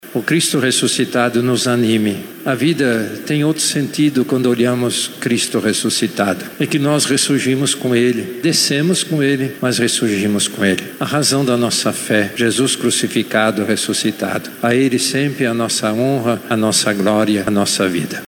A vitória de Cristo sobre a morte, representa a luz sobre as trevas, a força inabalável, na qual o cristão deve se firmar para uma vida nova, disse o Cardeal em sua homilia.